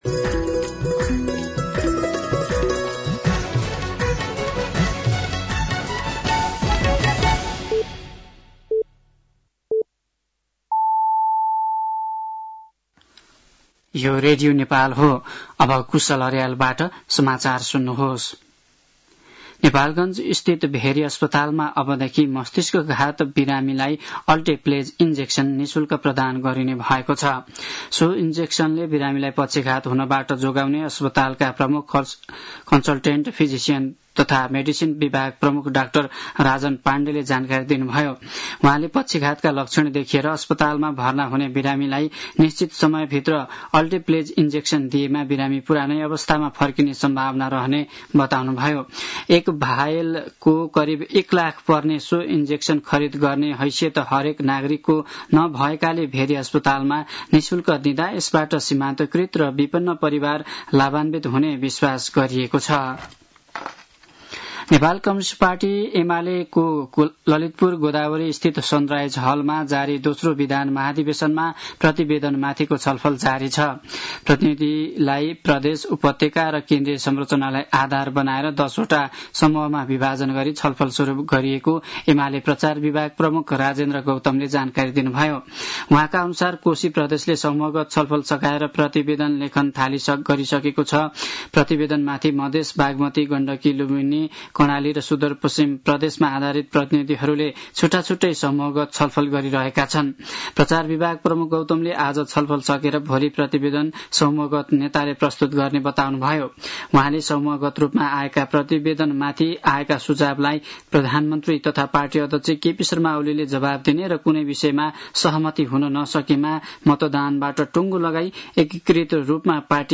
साँझ ५ बजेको नेपाली समाचार : २१ भदौ , २०८२